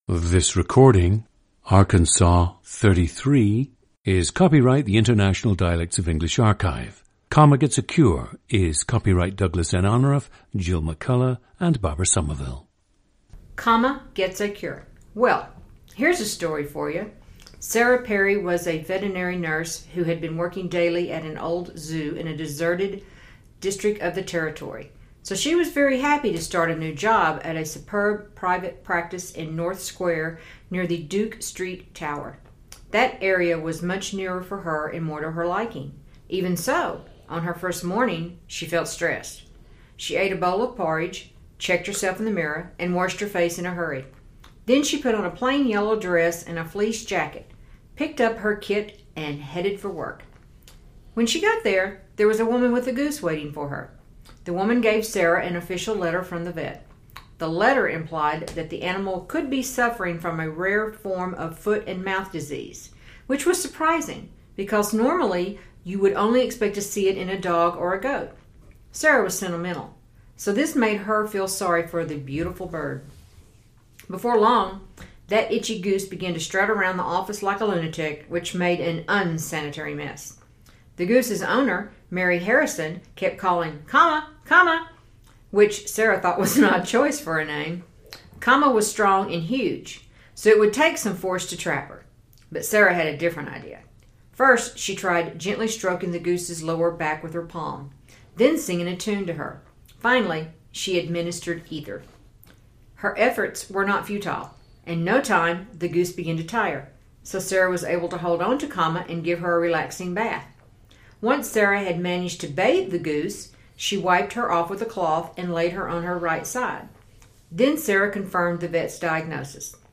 GENDER: female
/r/ is strong and lengthened (square, Sarah, mirror, rare, required). The /aɪ/ diphthong (PRICE) monophtongizes, reducing the diphthong to /a/ (private, liking, idea, my, rice, diagnosis, might, I). The /eɪ/ (FACE) diphthong often becomes /aɪ/ (PRICE) (plain, take, bank, paper).
/æ/ (TRAP) can possess a strong nasal quality and can become /ɛ/ (DRESS) (and, can, that).
The word “picture” is pronounced “pitcher.”
The recordings average four minutes in length and feature both the reading of one of two standard passages, and some unscripted speech.